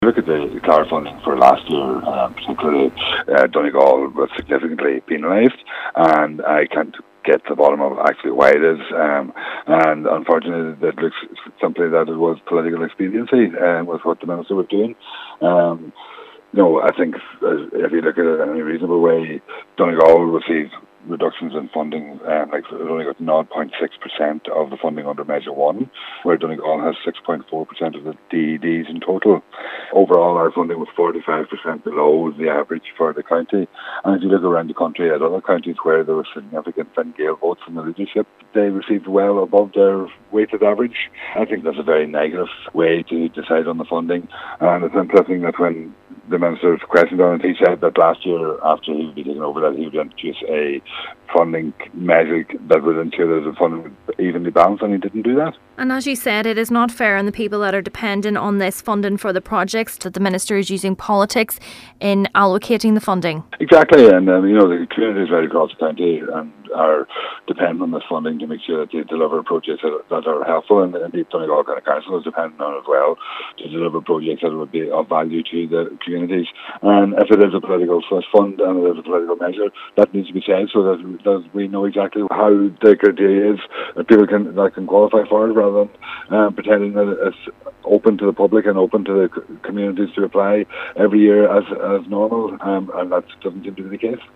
Deputy Pringle says serious questions need to be answered by the Minister: